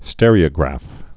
(stĕrē-ə-grăf, stîr-)